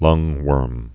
(lŭngwûrm)